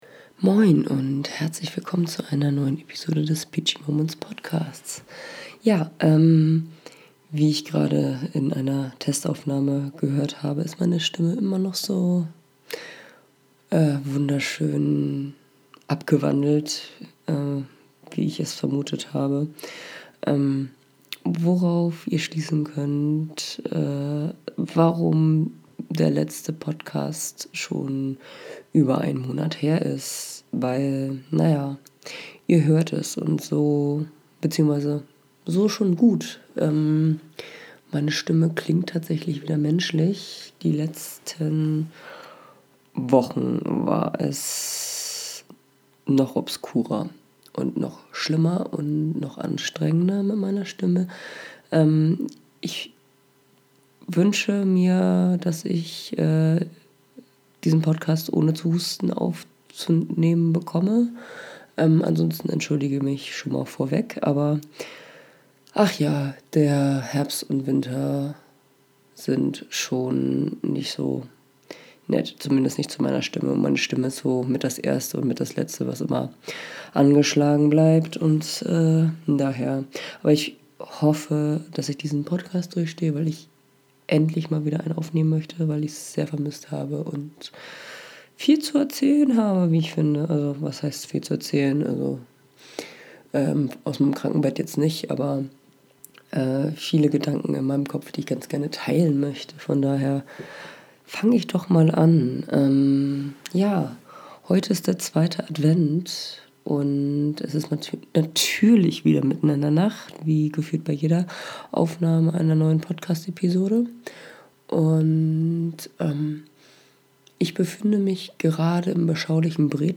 Meine Stimme hat mich im Stich gelassen & ist immer noch nicht wieder vollständig normal.